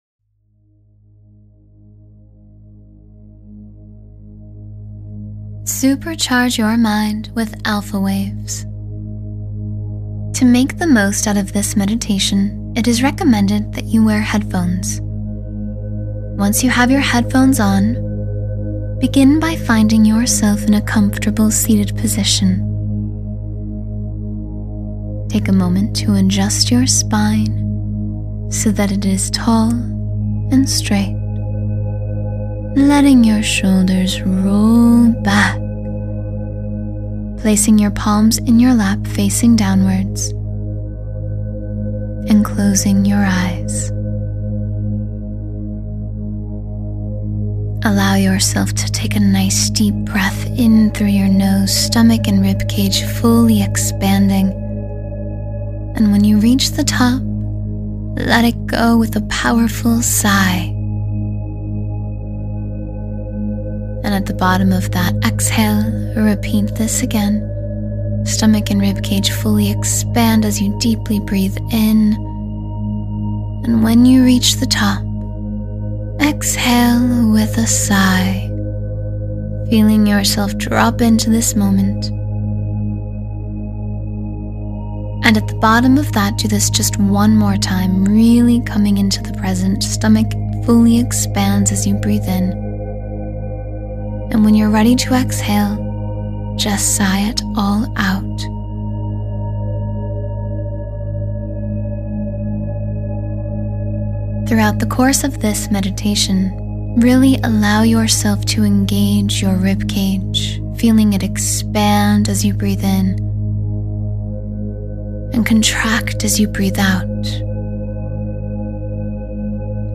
Deep Connection with All That Is — A 10-Minute Meditation to Align Yourself